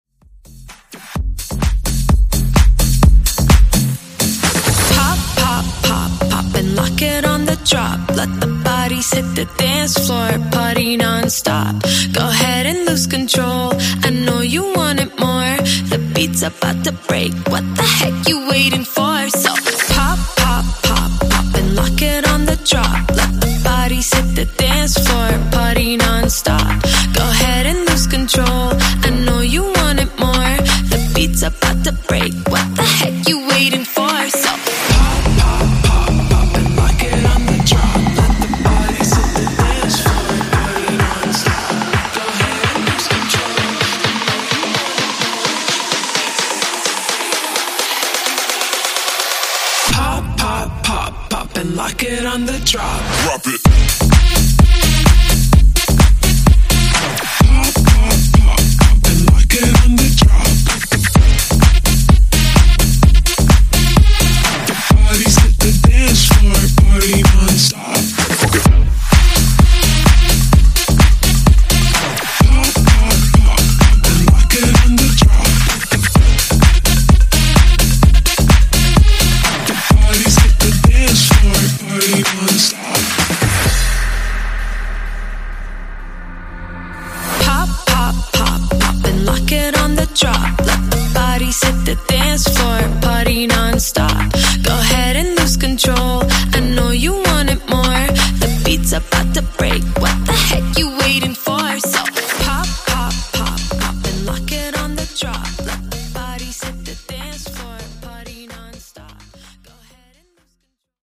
Genre: RE-DRUM
Clean BPM: 90 Time